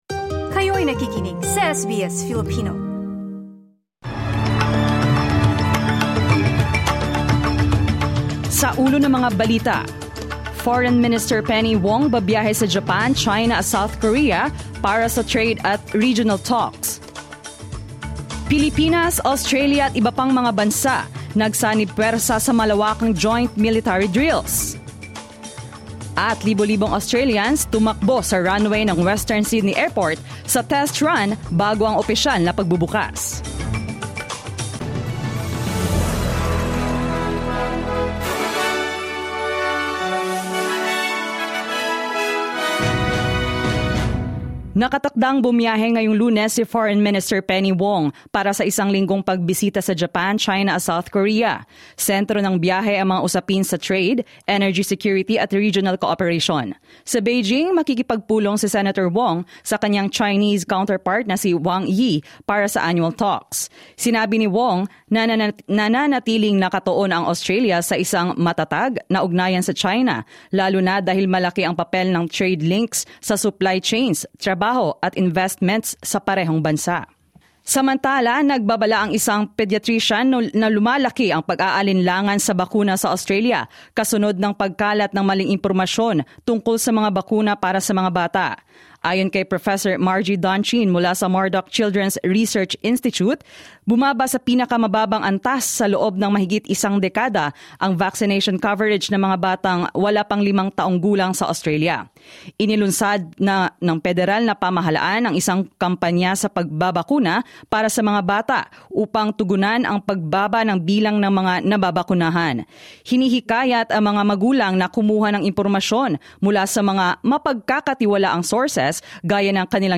SBS News in Filipino, Monday 27 April 2026